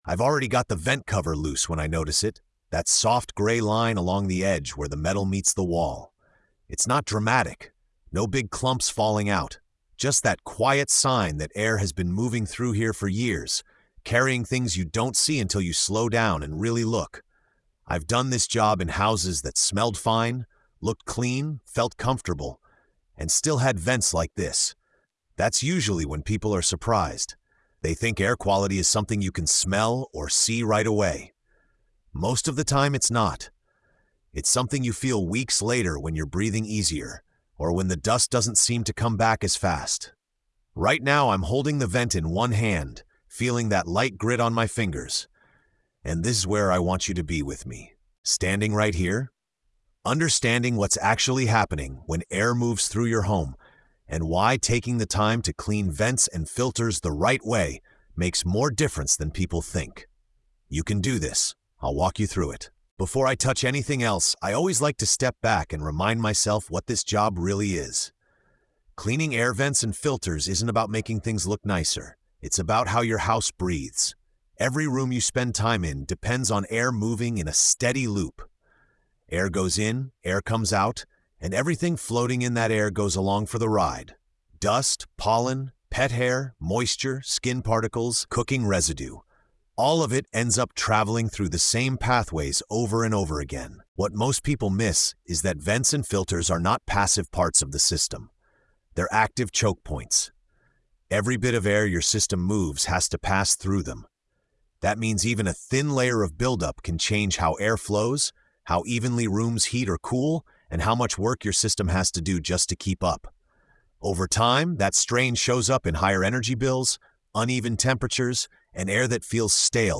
In this episode of TORQUE & TAPE — The Blue-Collar Skills, the listener is guided step by step through the often-overlooked task of cleaning air vents and replacing air filters for healthier indoor air. Told entirely in a calm, first-person mentor voice, the episode explains how air actually moves through a home, why dust and buildup quietly affect health and comfort, and how small details make a big difference.